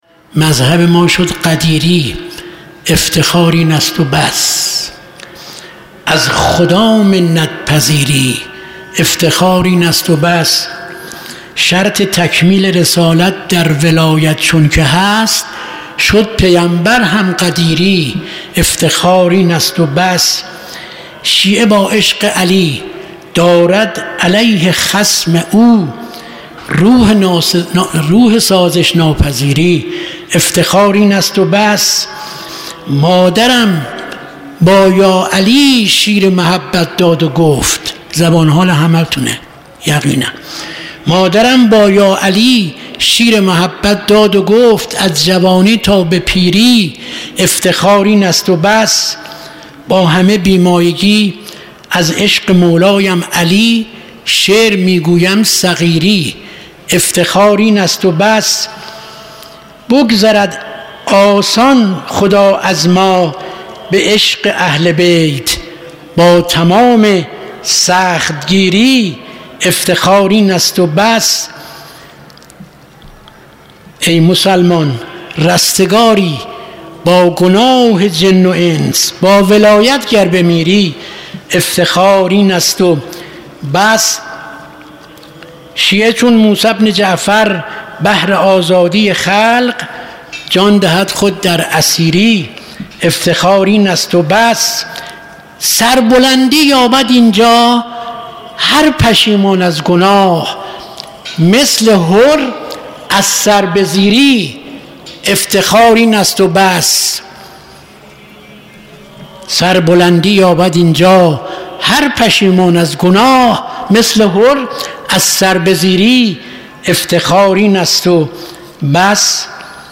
مداحی شب دوم محرم 1400 مدرسه فیضیه - هیئت ثارالله قم
هیئت ثارالله قم مراسم شب دوم دهه اول محرم شعرخوانی